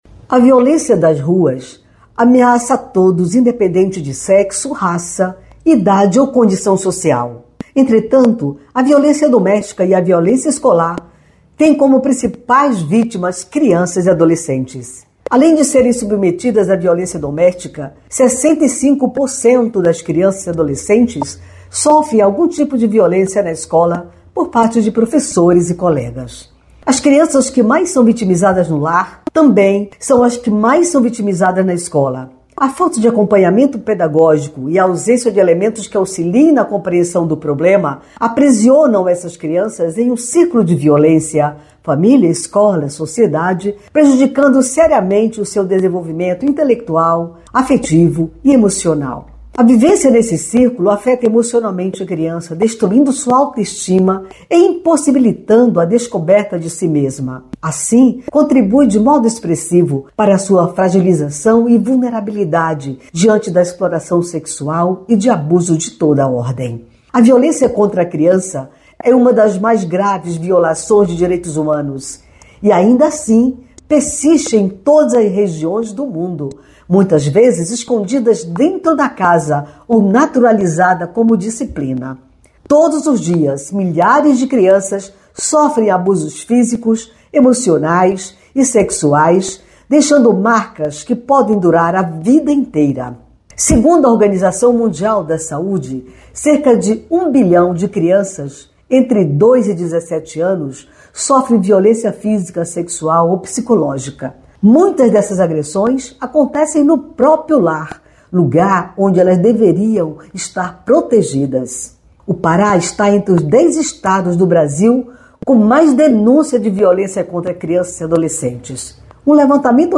Neste editorial